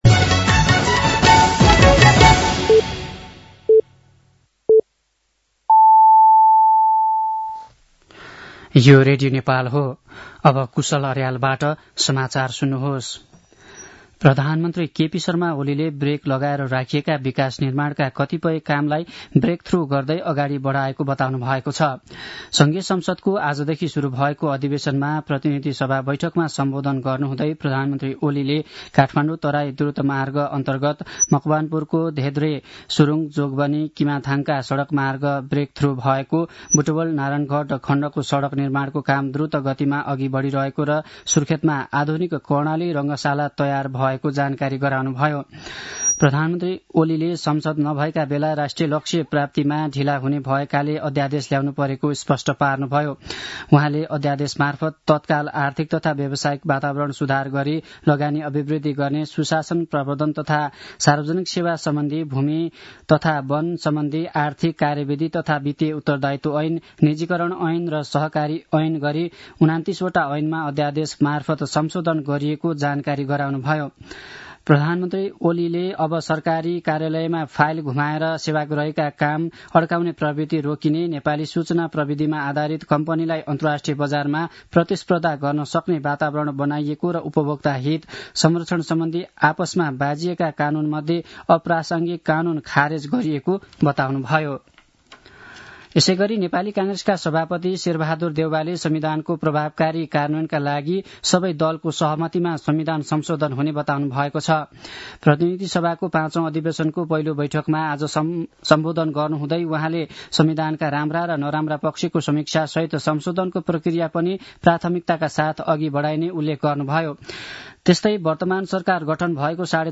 साँझ ५ बजेको नेपाली समाचार : १९ माघ , २०८१